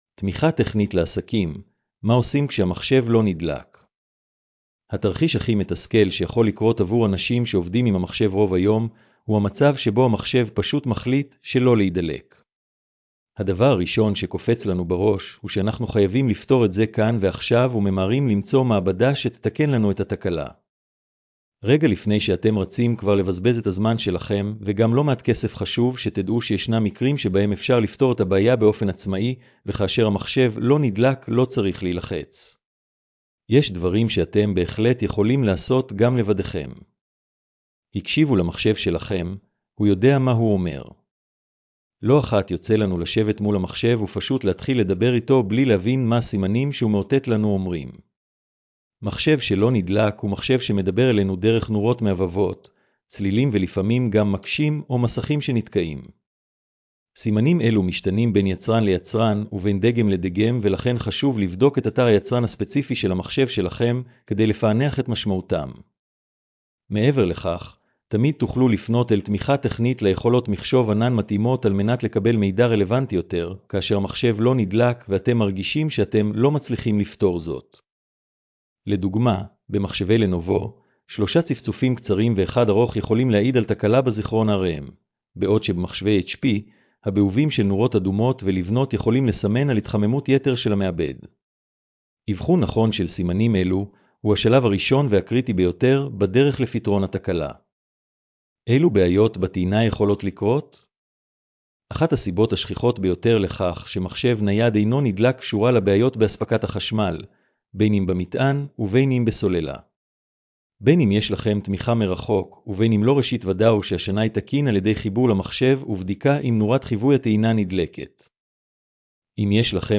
הקראת המאמר לאנשים עם מוגבלות: